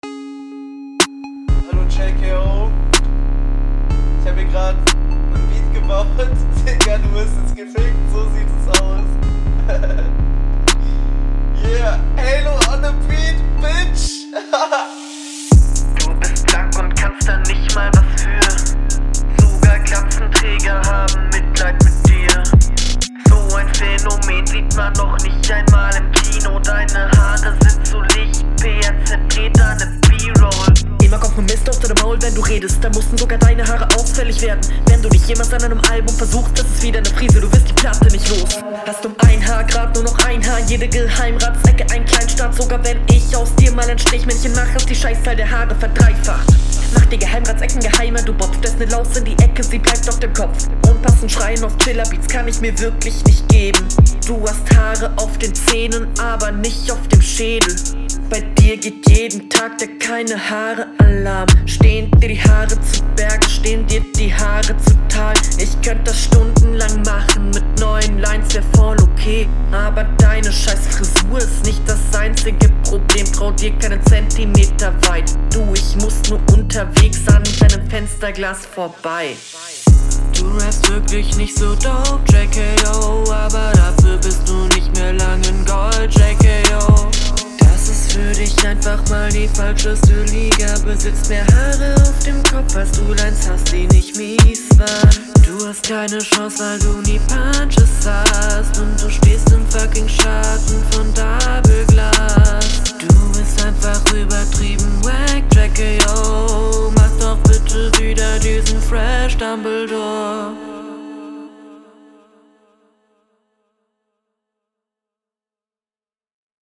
Auch gute Audio, das Intro ist ganz witzig, der Text ist sehr lustig, ich find …